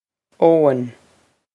Eoin Oh-in
This is an approximate phonetic pronunciation of the phrase.